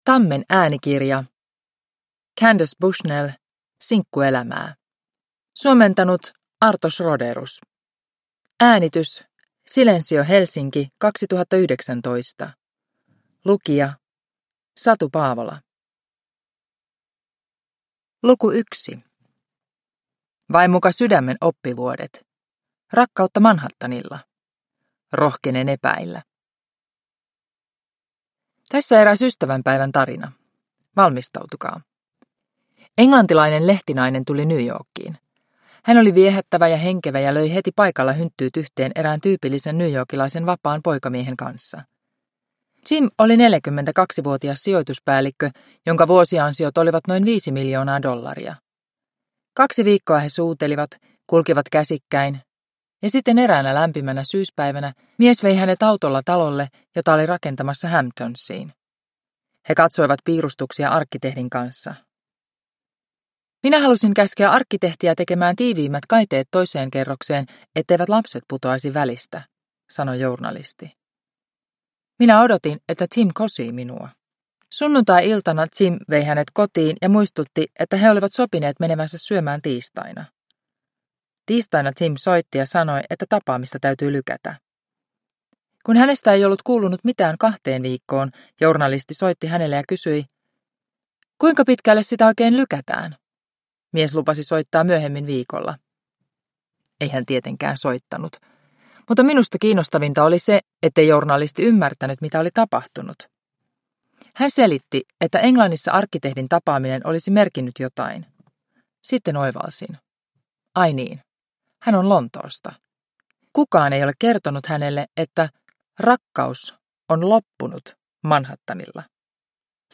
Sinkkuelämää – Ljudbok – Laddas ner